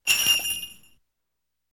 Sleigh Bells Sound Effect Free Download
Sleigh Bells